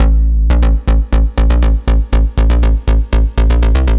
AM_OB-Bass_120-A.wav